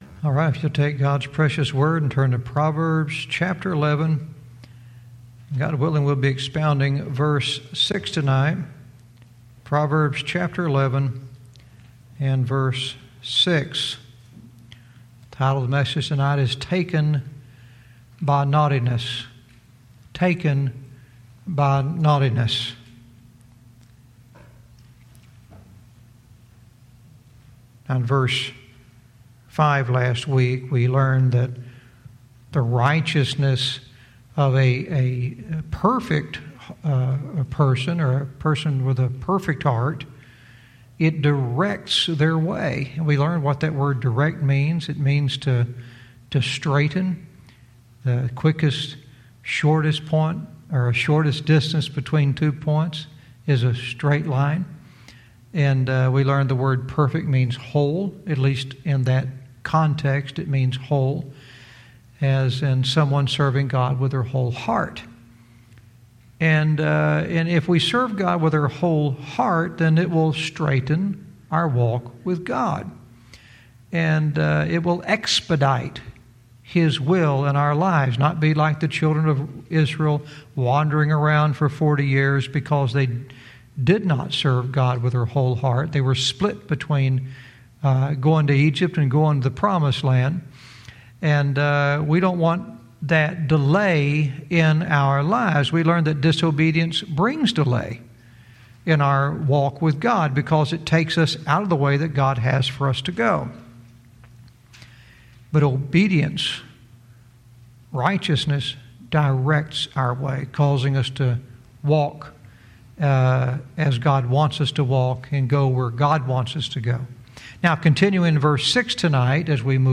Verse by verse teaching - Proverbs 11:6 "Taken by Naughtiness"